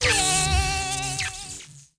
Npc Catzap Sound Effect
npc-catzap.mp3